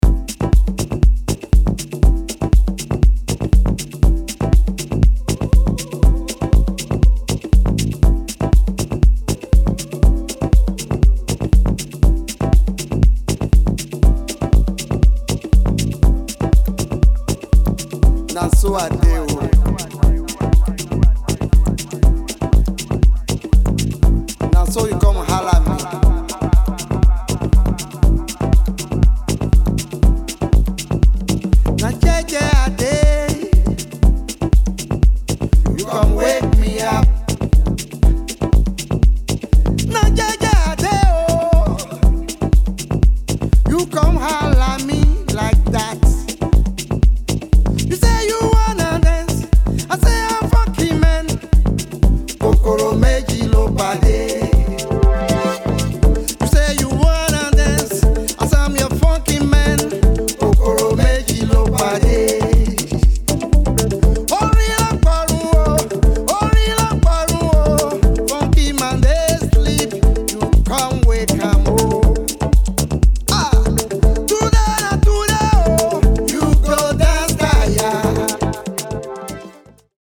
熱気を抑えながら、よりクールでディープなモダン・アフロ・ハウスへと仕立てたそちらも良し。